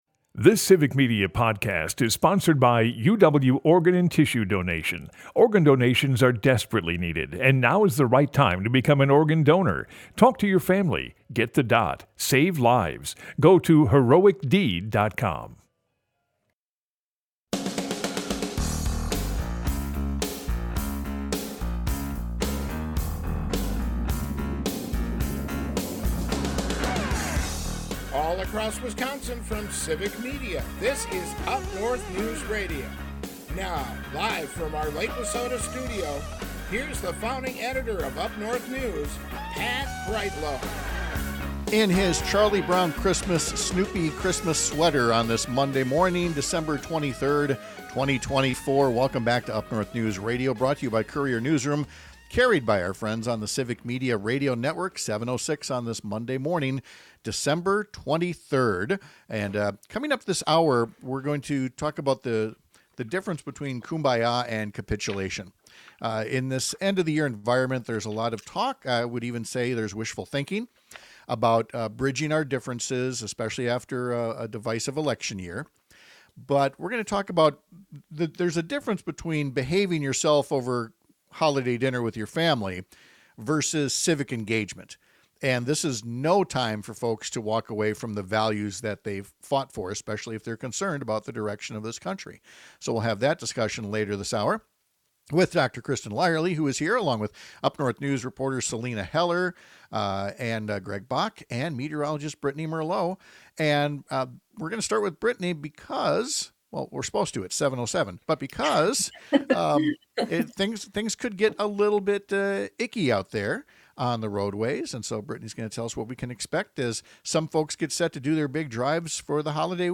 Broadcasts live 6 - 8 a.m. across the state!